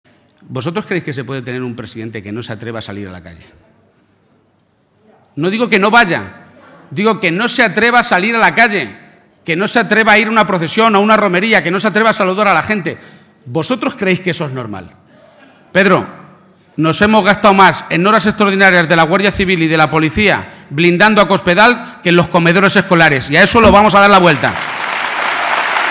Audio Page-acto Albacete 4